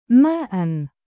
音标：māʾ